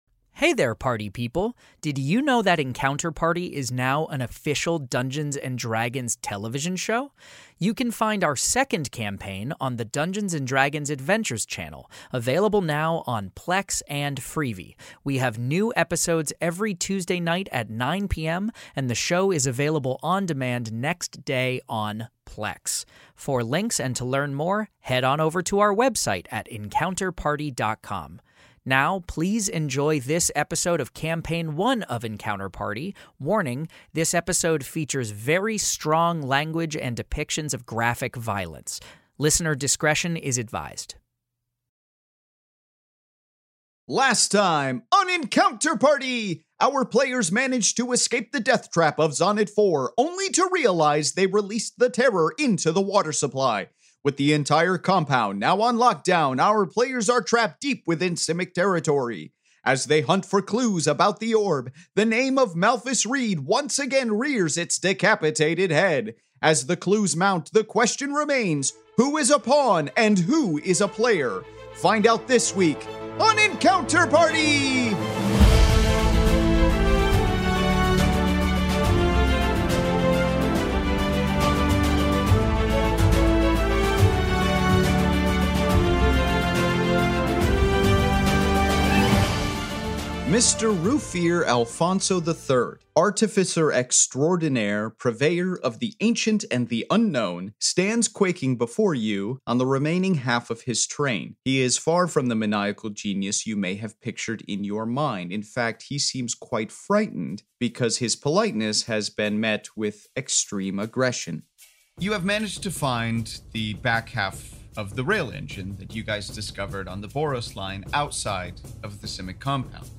Step inside the Magic: the Gathering world of Ravnica in this Fantasy Mystery Audio Adventure governed by the rules of Dungeons & Dragons